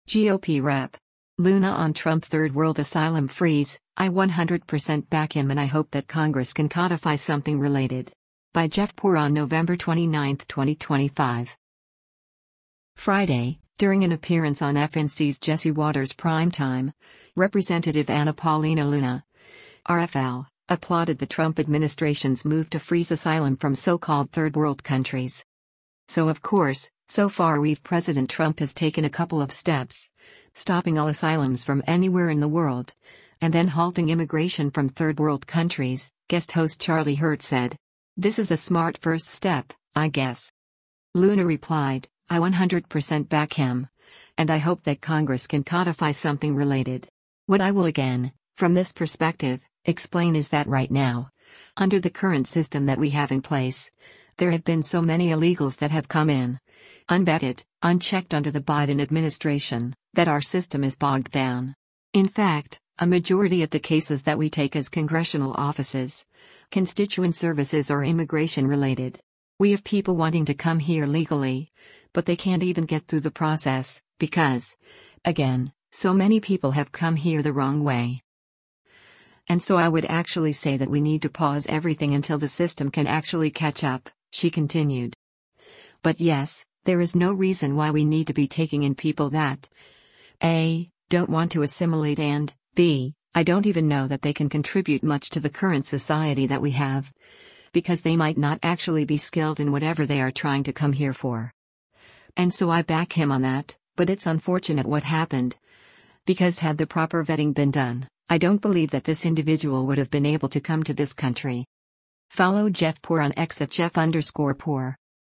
Friday, during an appearance on FNC’s “Jesse Watters Primetime,” Rep. Anna Paulina Luna (R-FL) applauded the Trump administration’s move to freeze asylum from so-called “third world countries.”